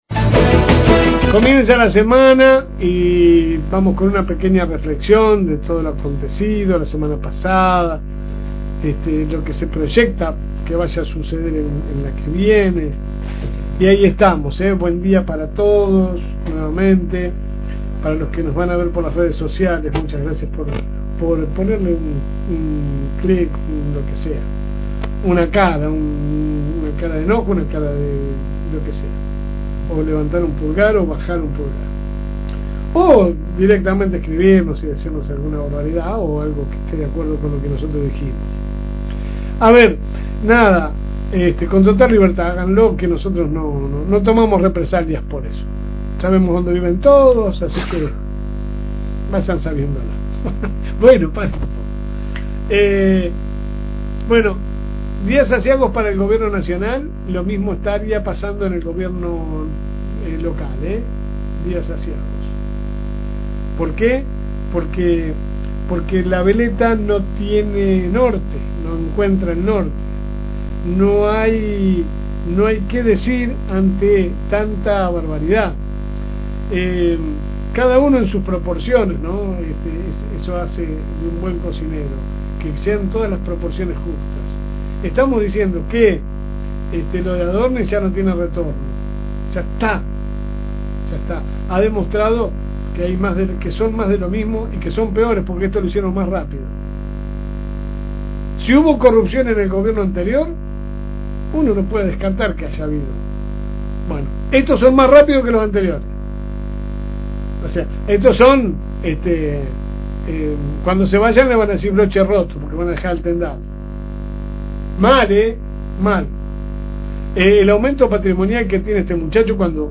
Editorial LSM
La editorial a continuación: